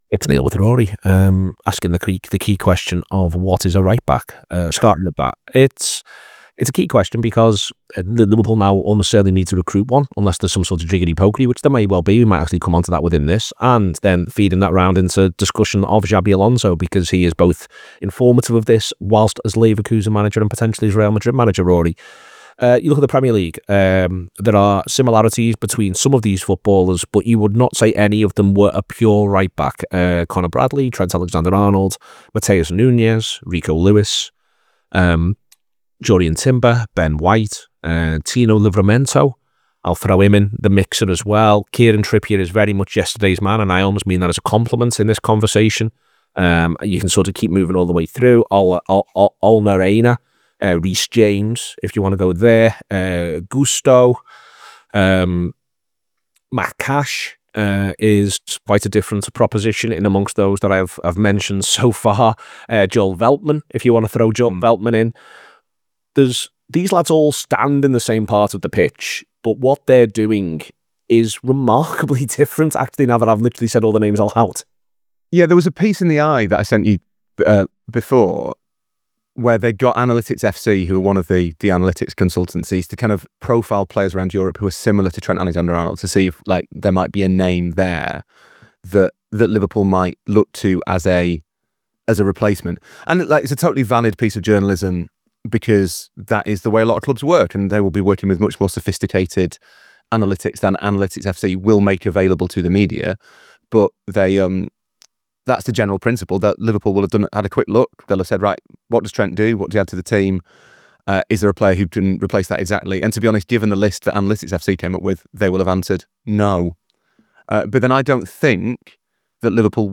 Below is a clip from the show – subscribe for a look at what Liverpool will look like after Alexander-Arnold’s departure…